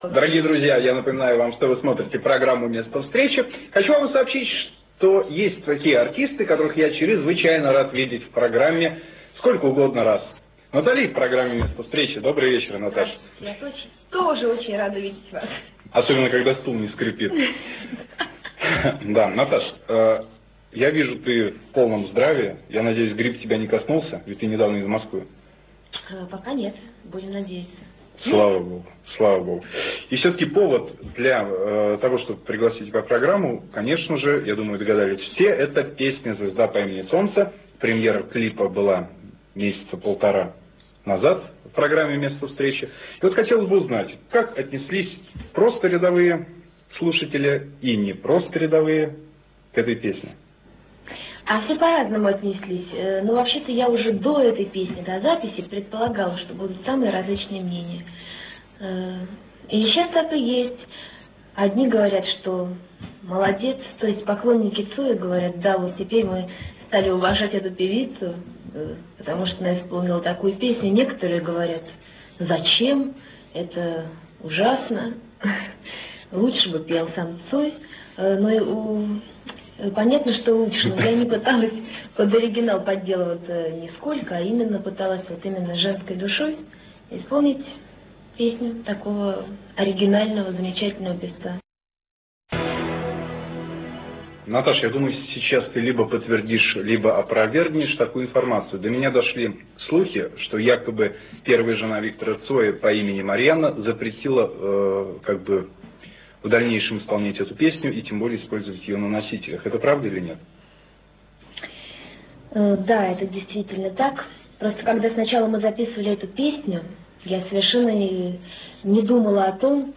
Канал ННТВ (Нижний Новгород) :: 1996-1997